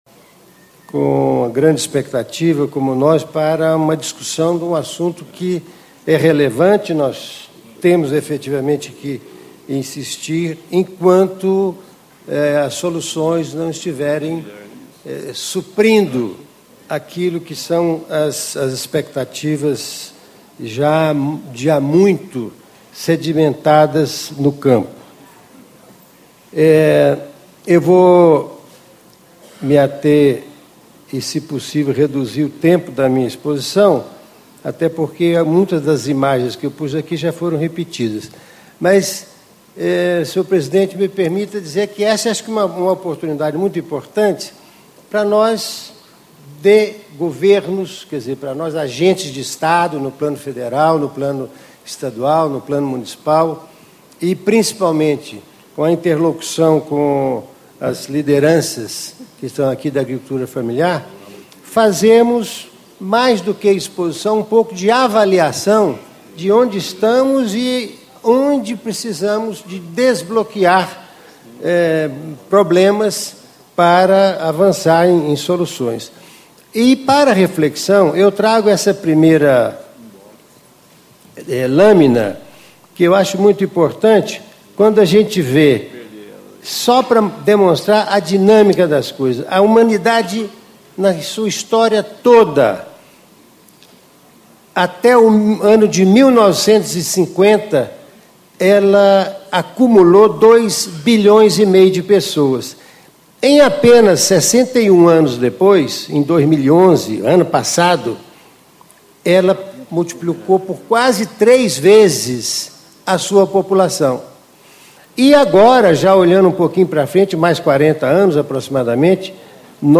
Paulo Afonso Romano, Secretário Adjunto de Estado de Agricultura, Pecuária e Abastecimento de Minas Gerais. Painel: Infraestrutura Rural e Acesso à Água
Discursos e Palestras